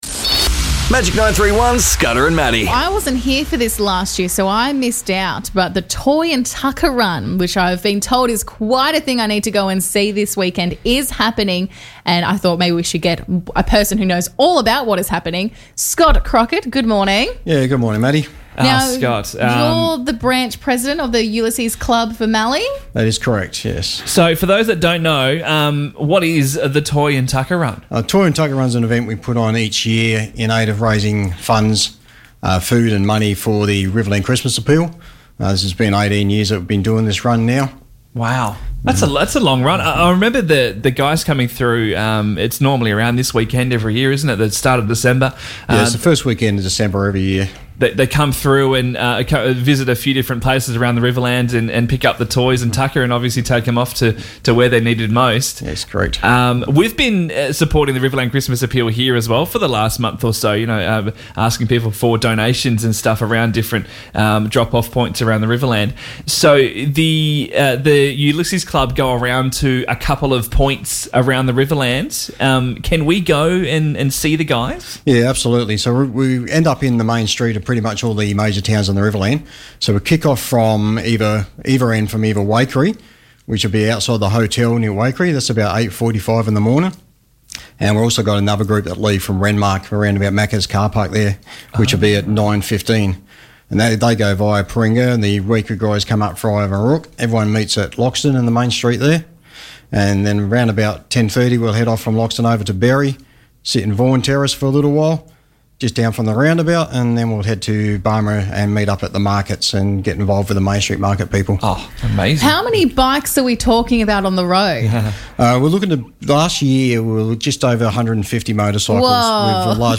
joined us on air this morning